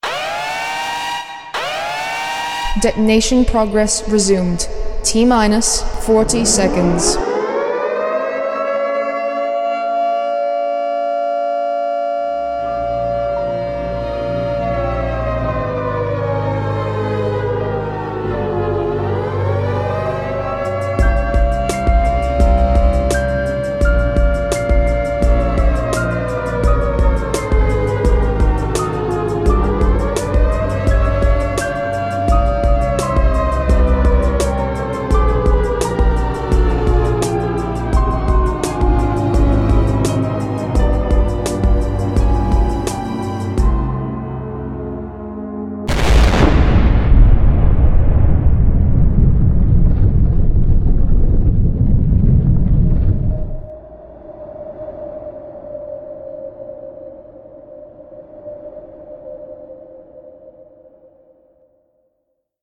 FemaleResume40.mp3